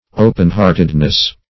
-- O"pen-heart`ed*ness , n. --Walton.
open-heartedness.mp3